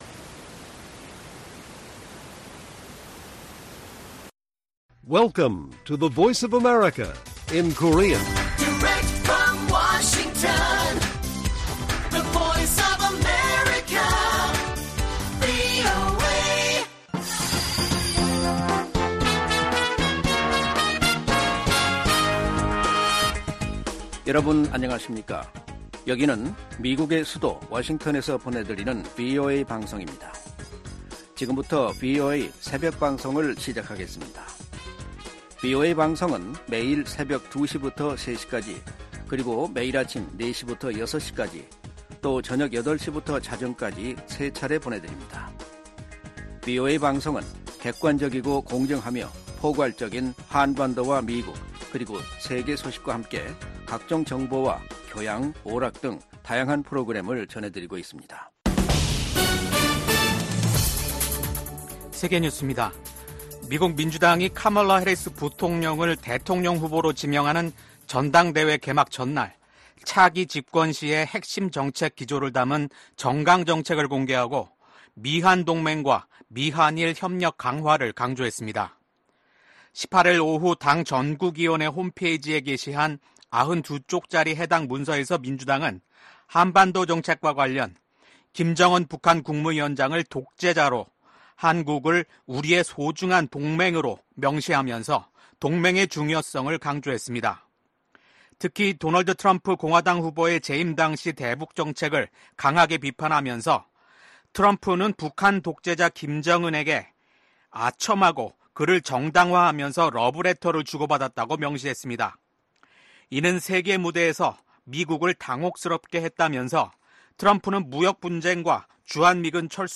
VOA 한국어 '출발 뉴스 쇼', 2024년 8월 20일 방송입니다. 미국과 한국, 일본이 캠프 데이비드 정상회의 1주년을 맞아 공동성명을 발표했습니다. 북한이 올해 말부터 제한적으로 외국인 관광을 재개할 것으로 알려진 가운데 미국은 자국민 방북을 절대 불허한다는 방침을 거듭 확인했습니다. 미국 정부는 윤석열 한국 대통령이 발표한 ‘8.15 통일 독트린’에 대한 지지 입장을 밝혔습니다.